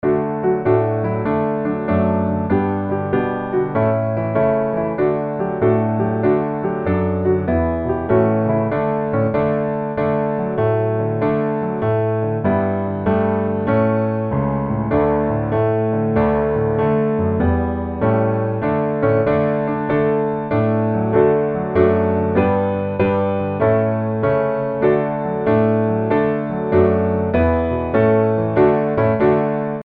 Gospel
D Majeur